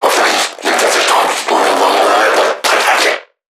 NPC_Creatures_Vocalisations_Infected [57].wav